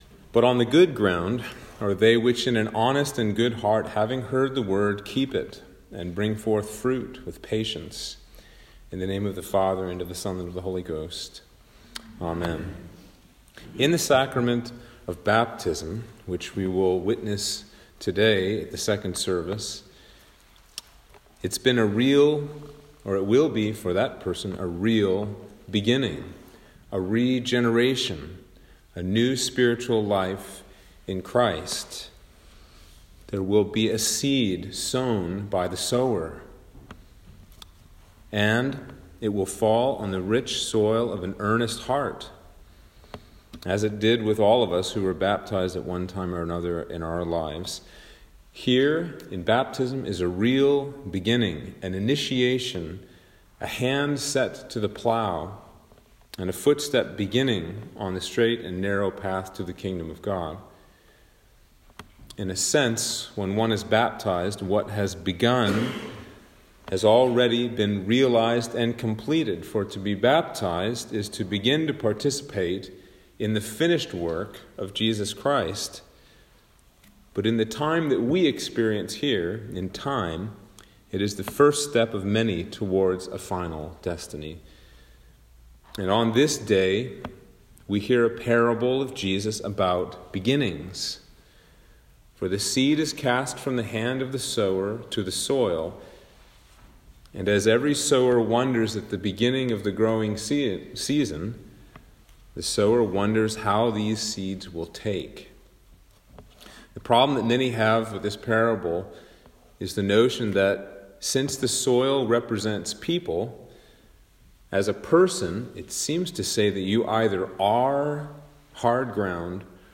Sermon for Sexagesima - 2022